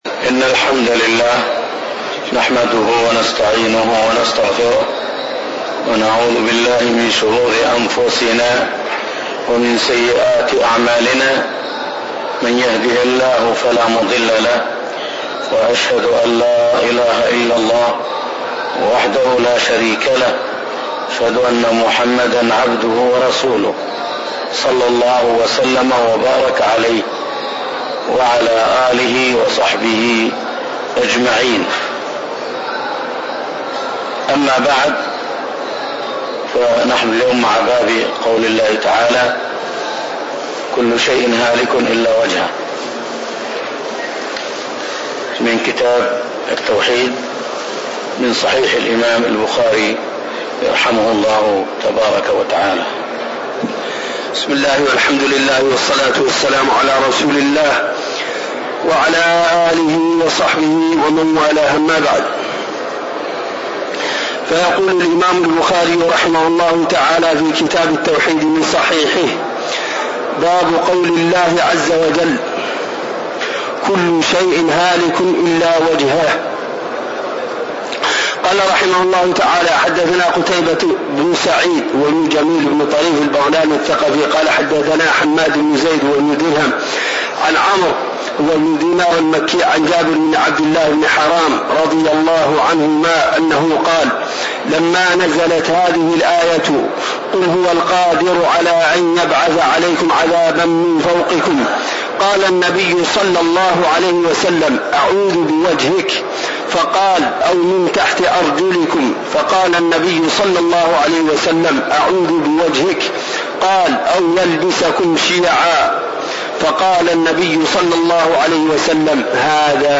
تاريخ النشر ١ جمادى الأولى ١٤٣٣ هـ المكان: المسجد النبوي الشيخ